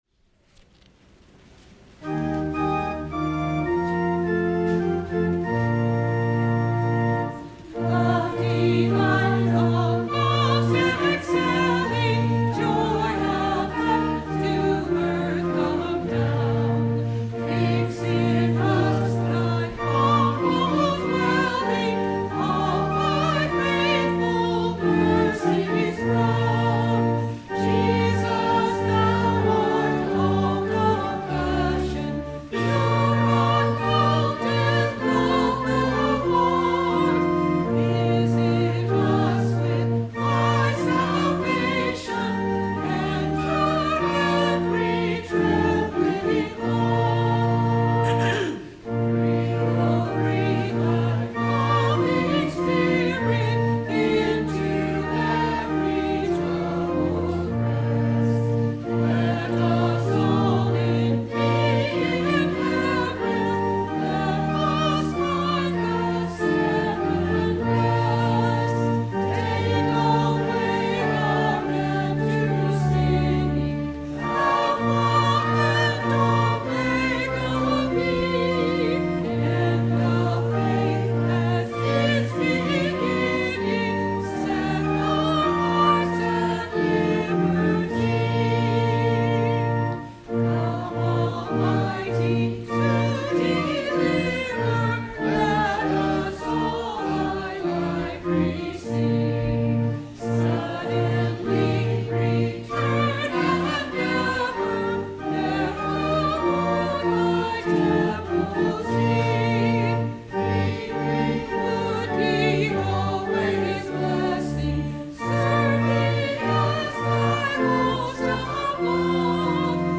Sung by the Church and Choir.